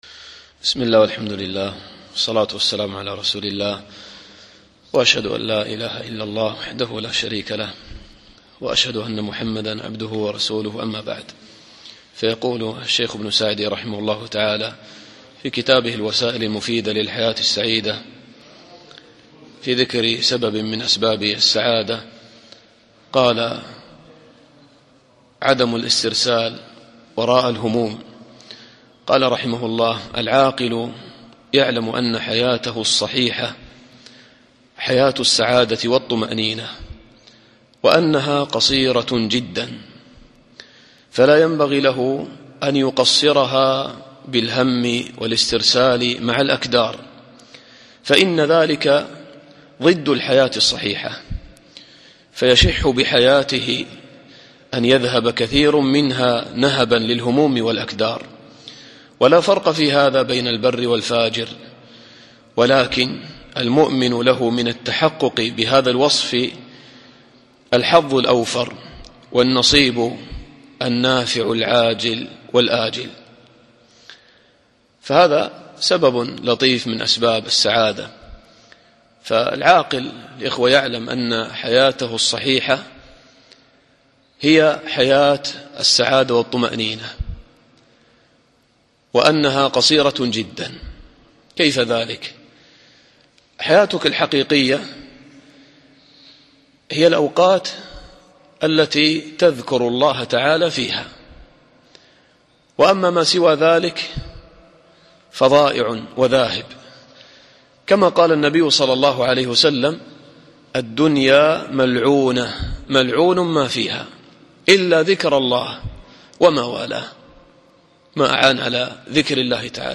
الدرس الخامس عشر